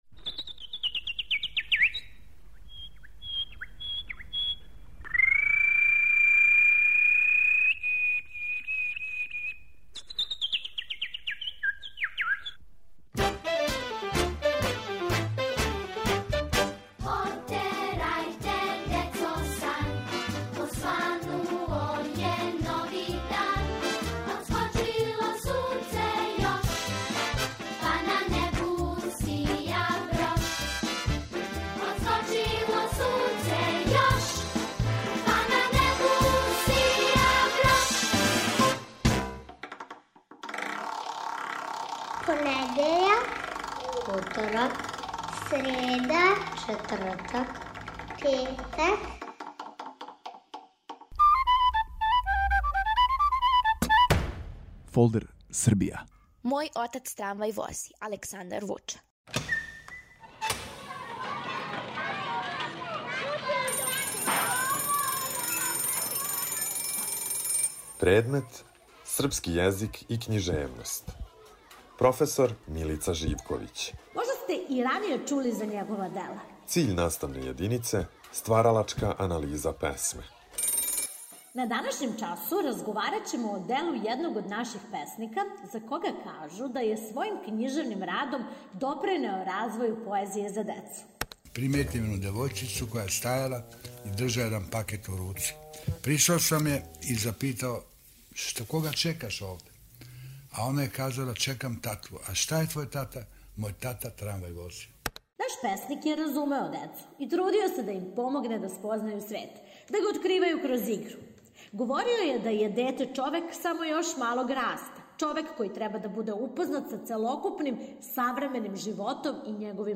Серијал "Фолдер Србија" води вас на час српског језика и књижености.